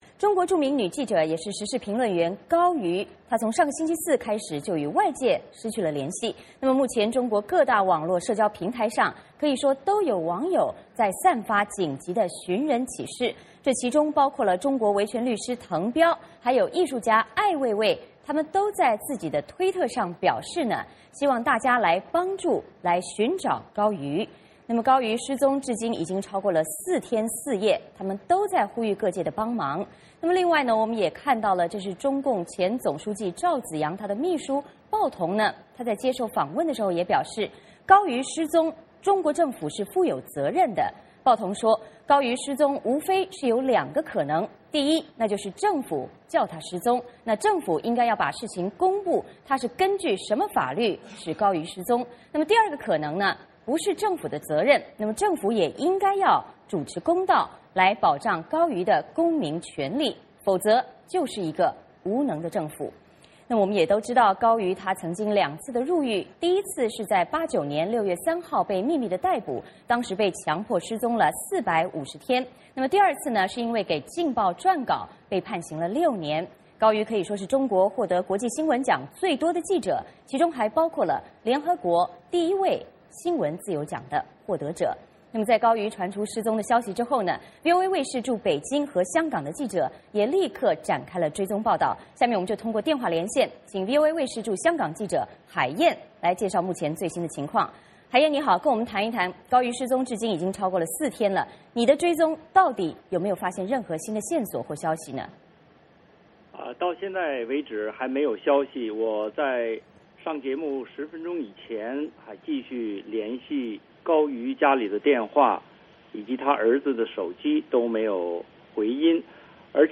VOA连线：中国女记者高瑜失踪，各界紧急寻人